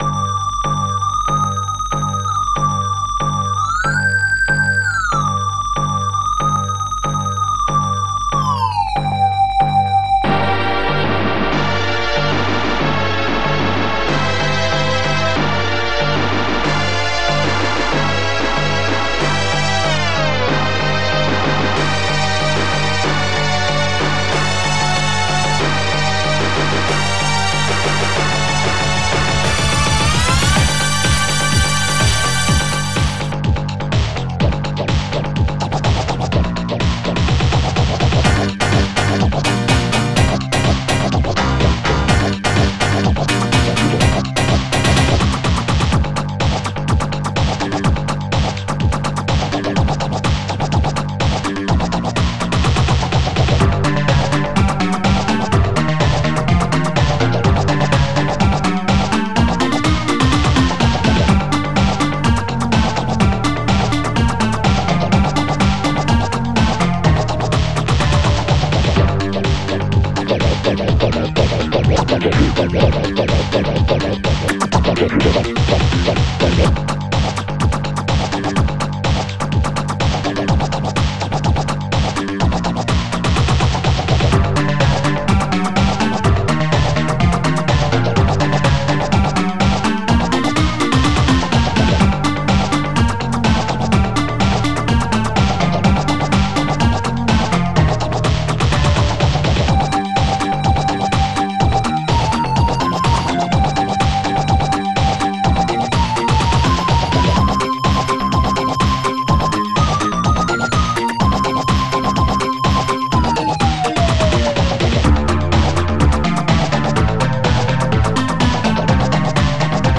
Protracker and family
ST-05:orch3
-4ST-12:housemaj
ST-12:housescratch1
ST-12:amigcomdrum
ST-28:clabibass.01